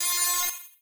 retro_fail_alarm_01.wav